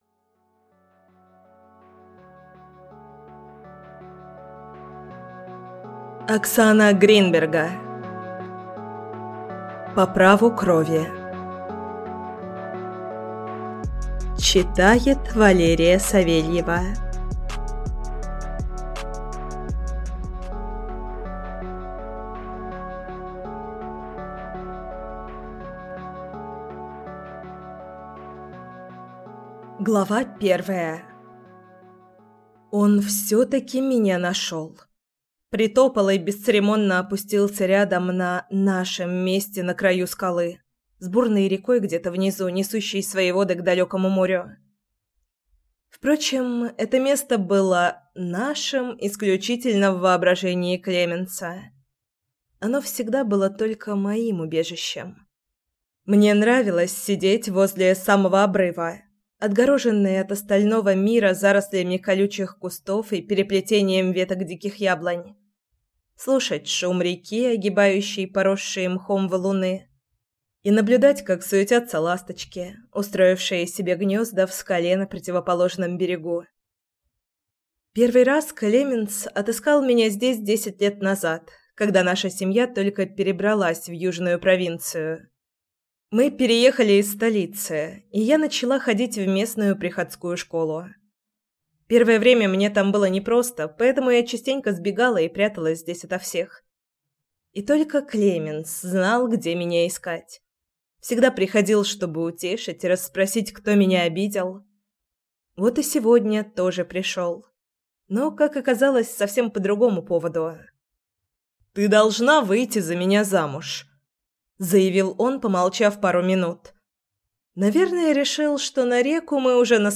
Аудиокнига По Праву Крови | Библиотека аудиокниг